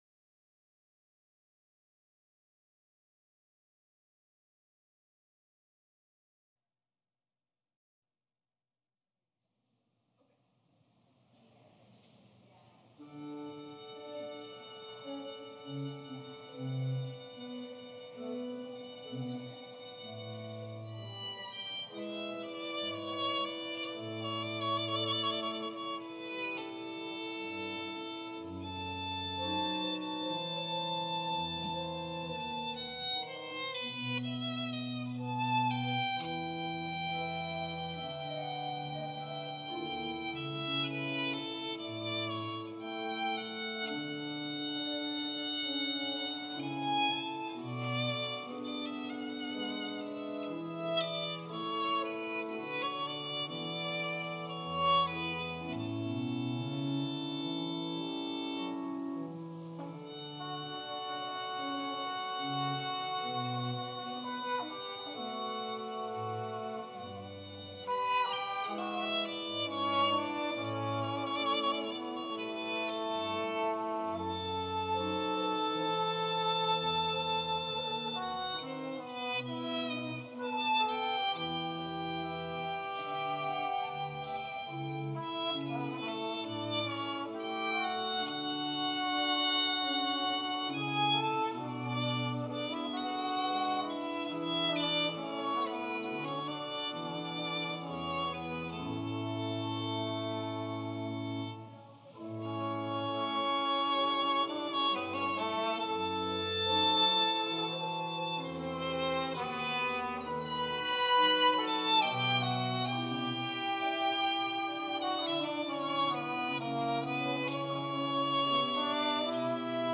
1-12 Service Type: Special Service Scriptures and sermon from St. John’s Presbyterian Church on Sunday